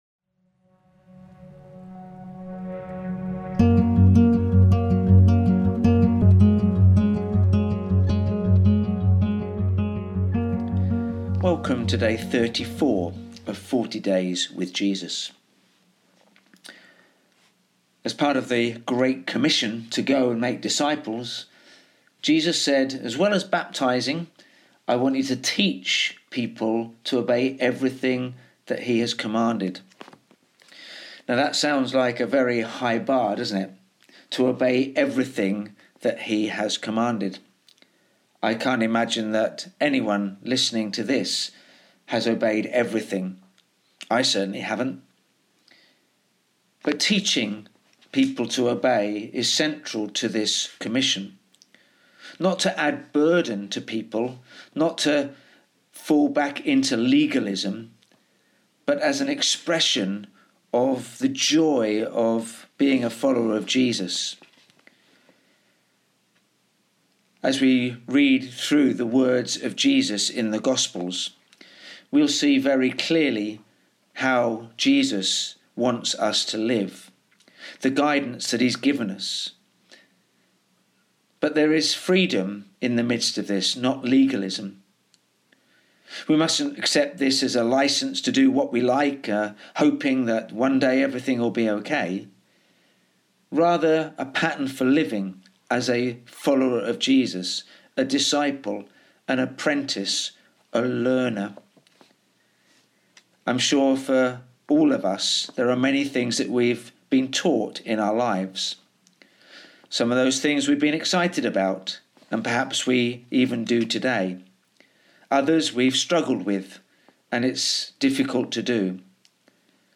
We will be posting short, daily reflections as we journey through the encounters people had with the risen Jesus.